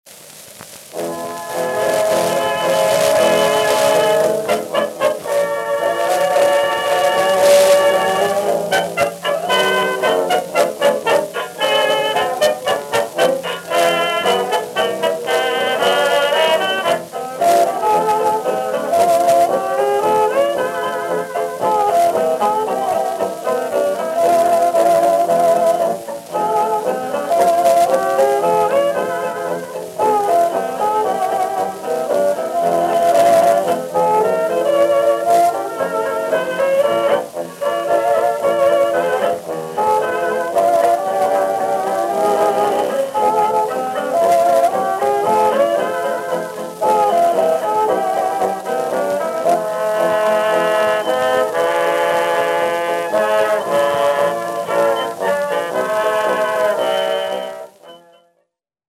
Flat, De-Click 3.0Mil, 250Hz boost